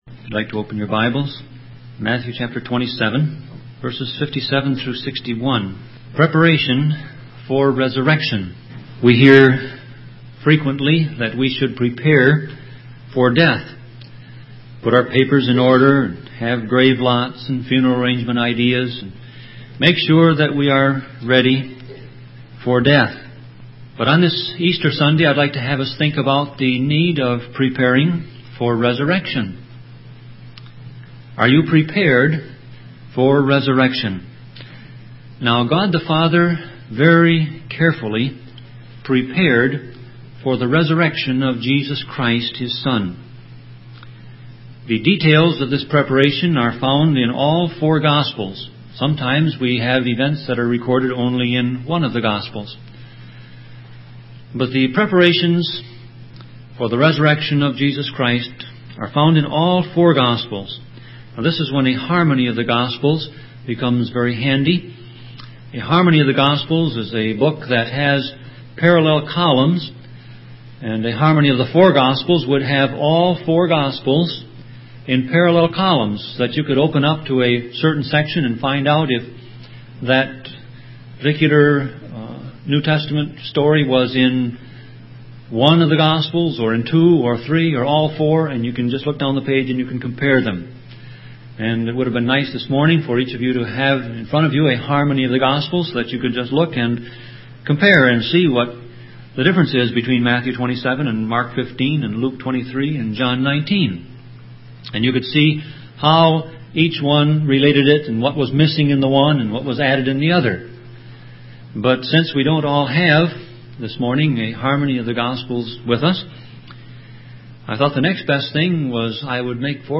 Series: Sermon Audio Passage: Matthew 27:57-61 Service Type